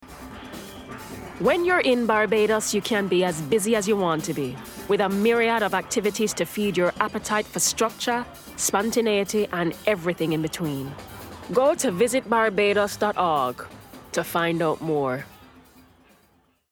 Confident/Engaging/Soothing
Visit Barbados (West Indian accent)